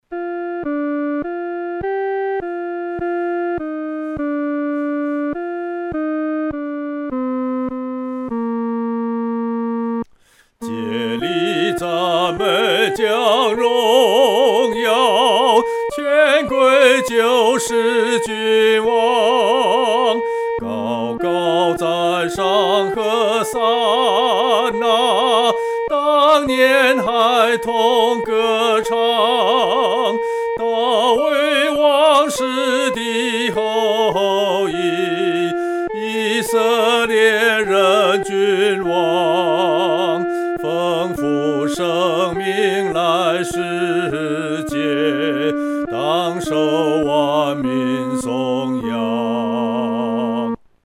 独唱（第一声）